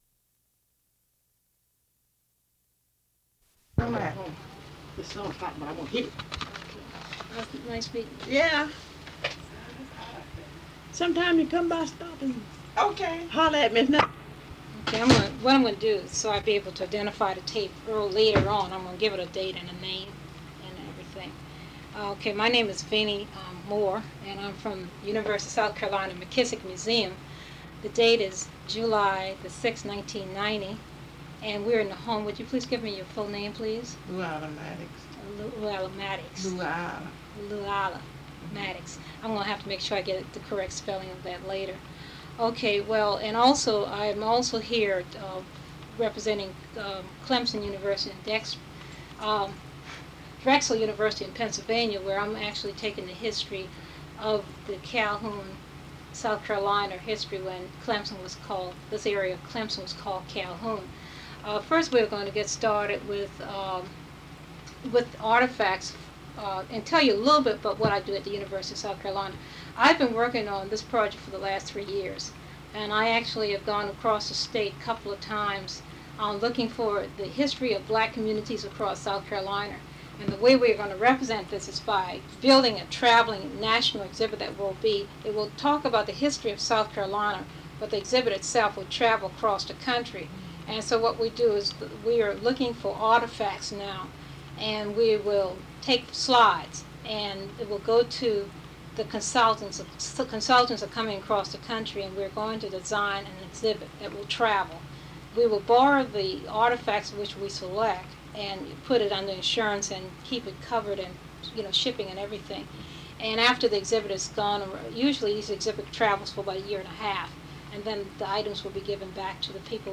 Part of Interview